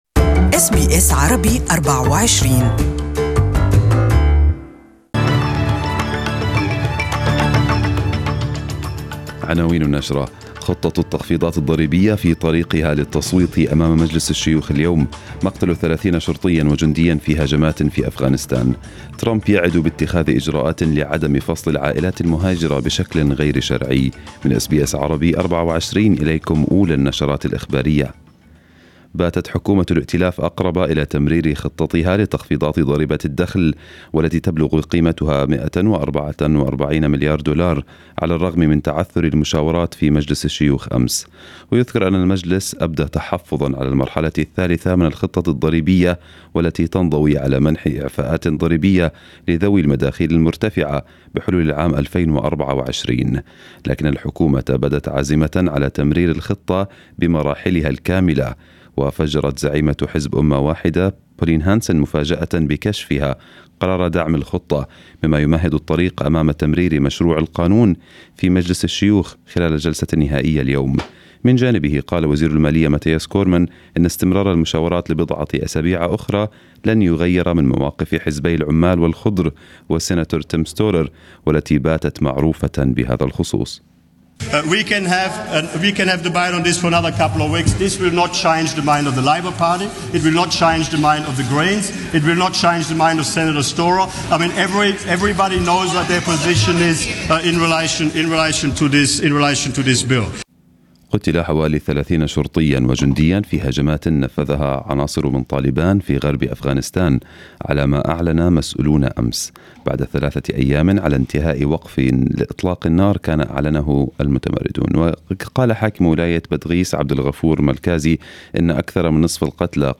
Arabic News Bulletin 21/06/2018